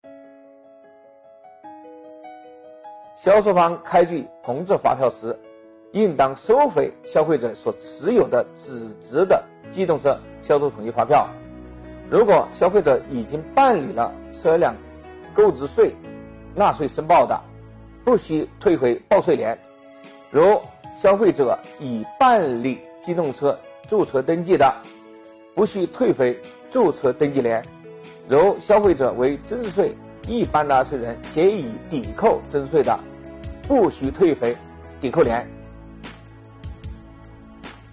近日，国家税务总局推出“税务讲堂”课程，国家税务总局货物和劳务税司副司长张卫详细解读《办法》相关政策规定。销售方开具红字机动车销售统一发票时如何处理原蓝字发票？